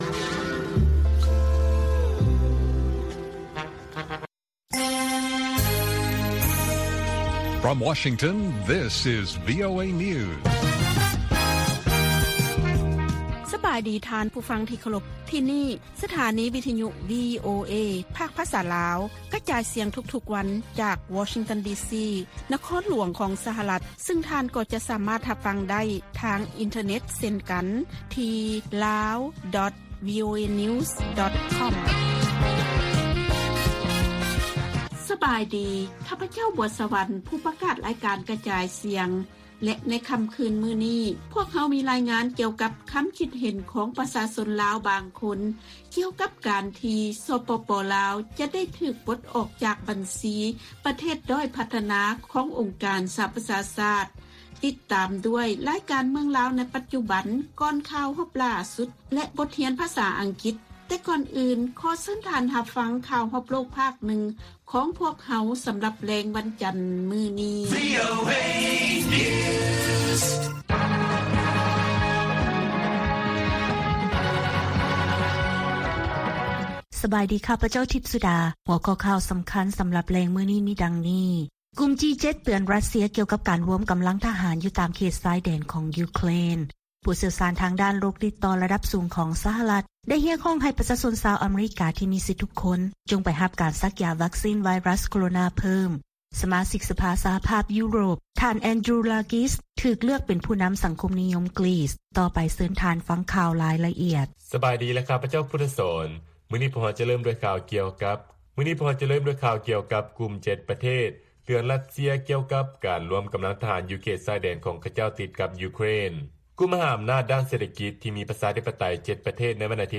ລາຍການກະຈາຍສຽງຂອງວີໂອເອ ລາວ: ກຸ່ມ G-7 ເຕືອນ ຣັດເຊຍ ກ່ຽວກັບການລວມກຳລັງທະຫານ ຢູ່ຕາມເຂດຊາຍແດນຂອງ ຢູເຄຣນ